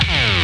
szum.mp3